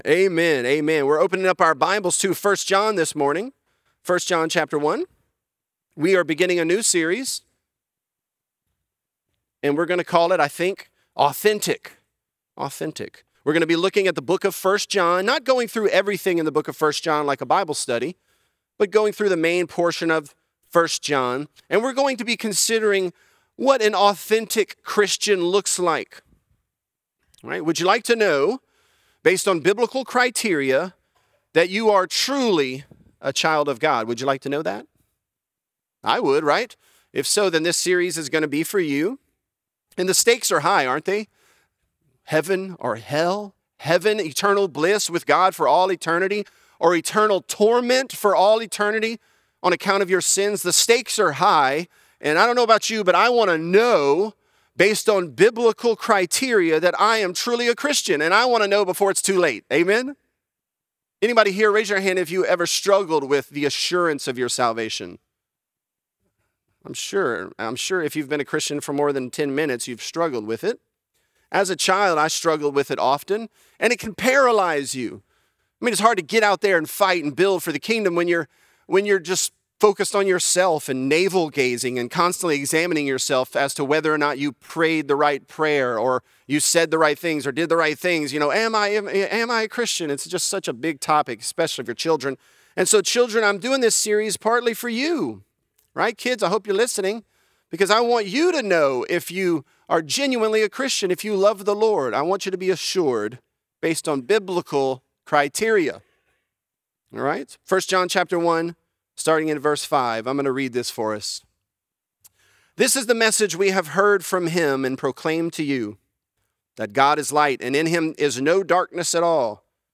Authentic: A Real Christian | Lafayette - Sermon (1 John 1)